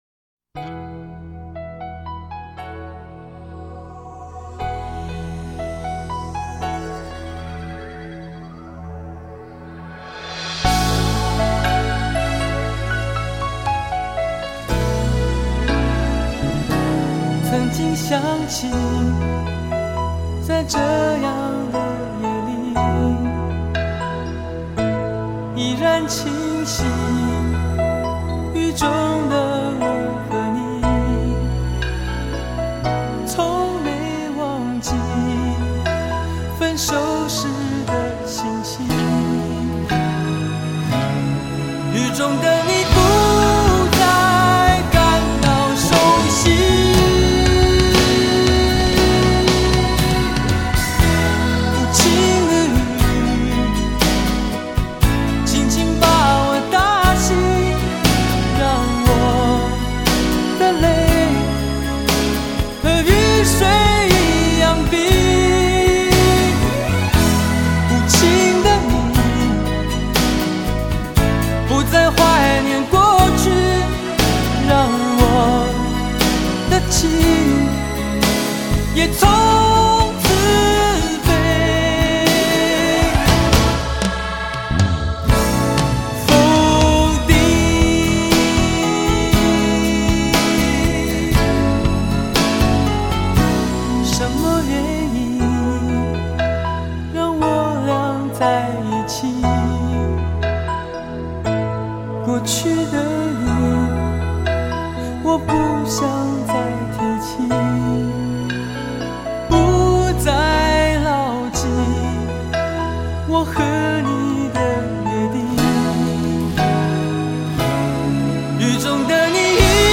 歌曲经过重新混音后层次感更分明、人声更华丽。